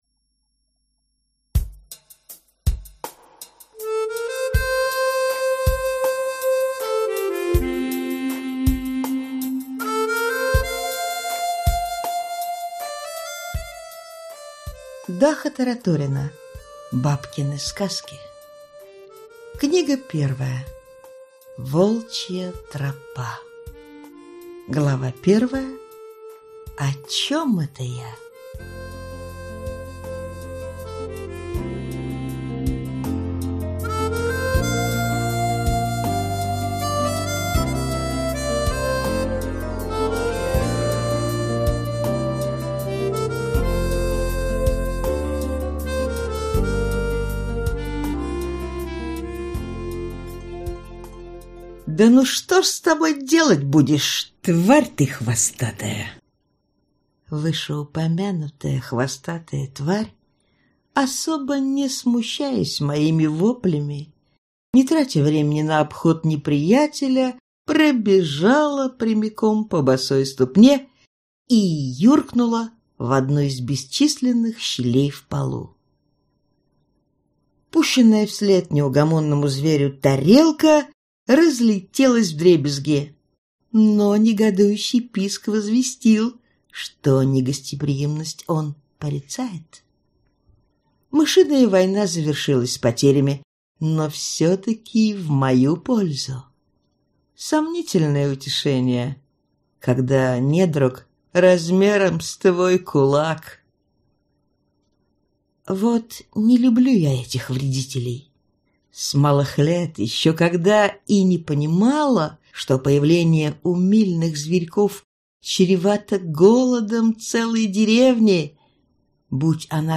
Аудиокнига Волчья тропа | Библиотека аудиокниг